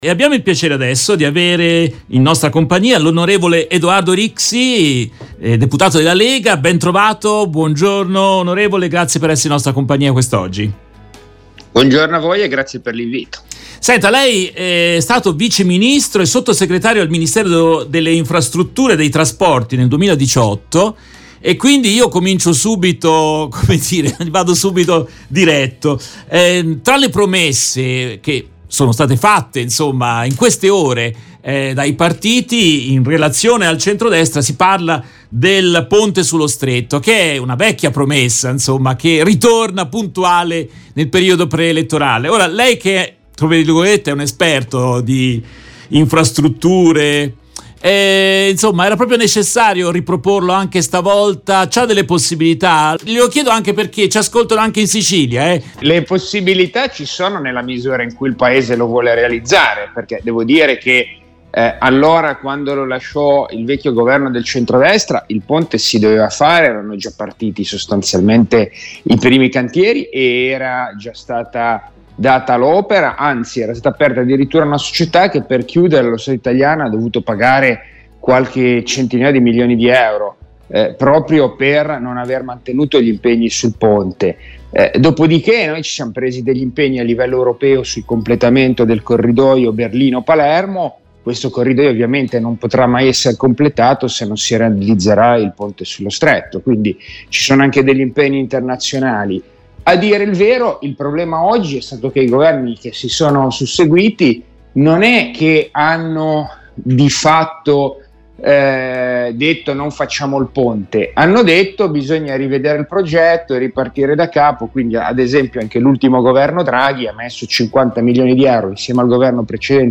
In questa trasmissione intervistano l'on. Edoardo Rixi (Lega), già Viceministro e sottosegretario al Ministero delle infrastrutture e dei trasporti (2018). Tra i temi affrontati: le promesse elettorali del Centrodestra: ricompare il ponte sullo Stretto di Messina, la flat tax, presidenzialismo e autonomie locali, la riforma della Giustizia.